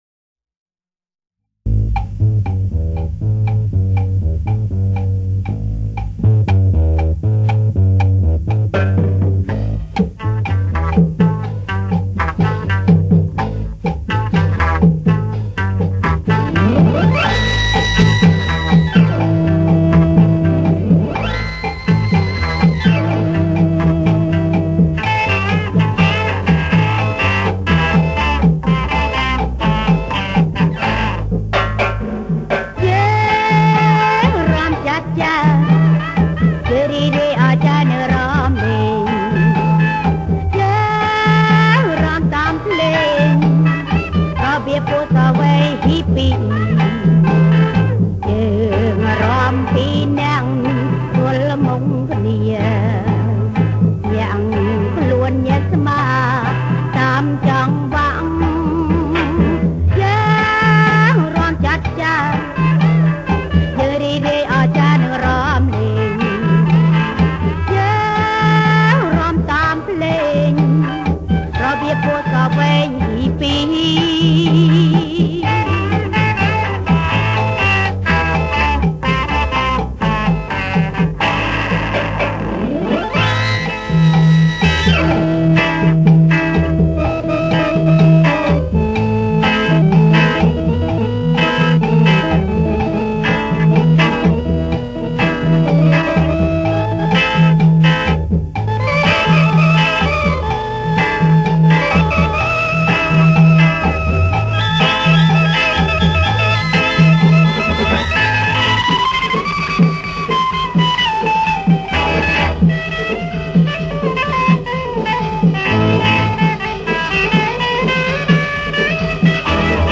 ប្រគំជាចង្វាក់ Cha Cha Cha